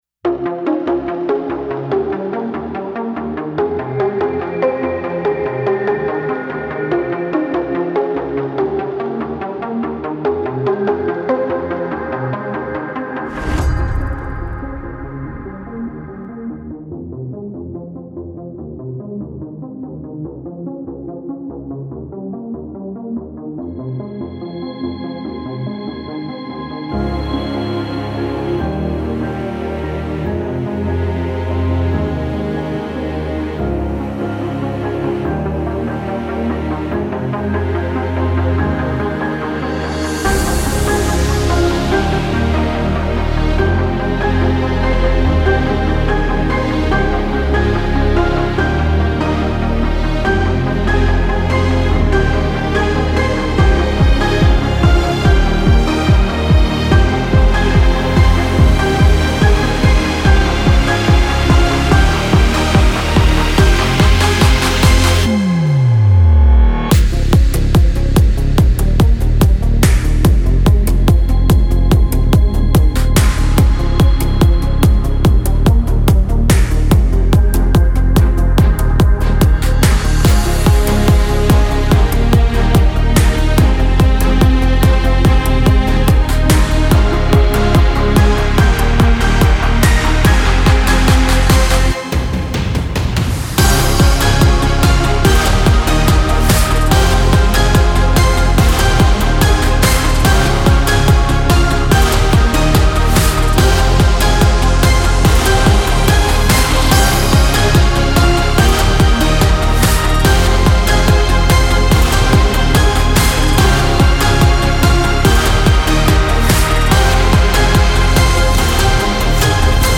Характер песни: позитивный.
Темп песни: средний.
• Минусовка